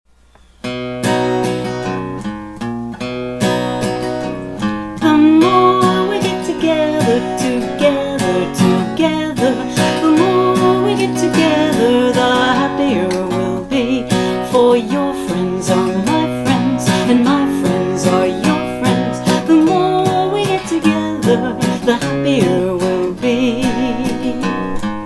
Traditional Children's Song Lyrics and Sound Clip